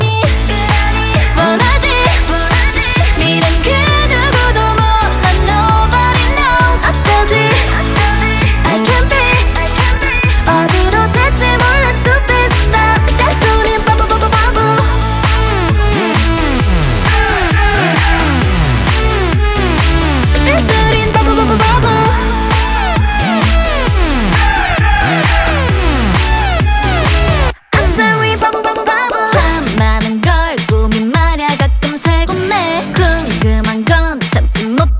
Жанр: K-pop / Поп / Русские